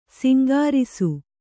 ♪ singārisu